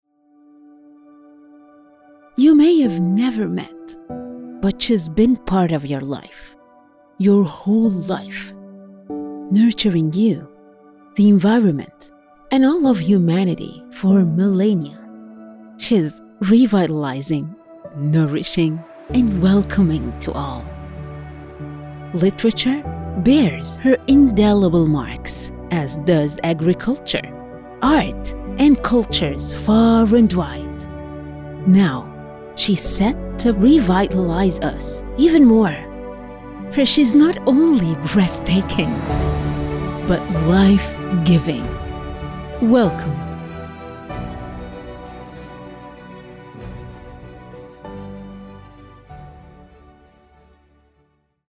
Female
Story Telling Genam-Middle Eas
Words that describe my voice are Warm, Conversational.